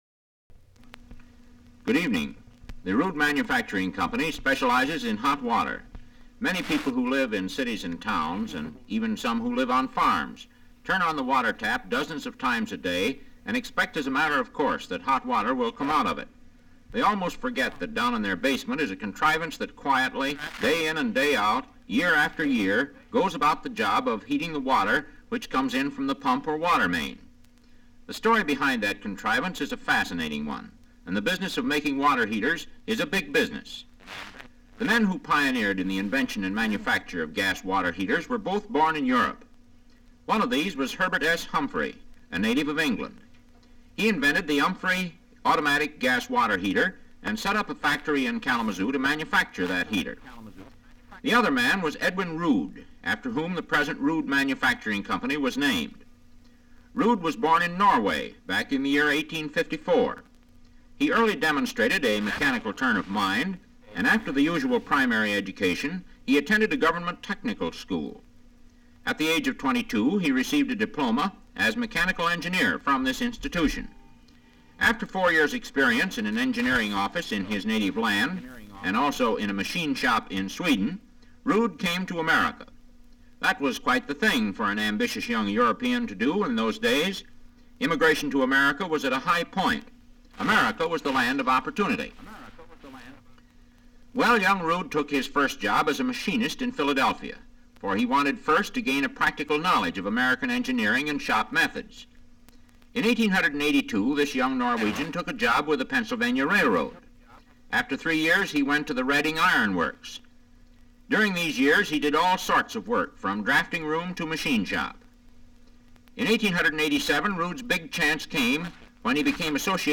Sound recordings Interviews Radio programs
Broadcast 1947 January 20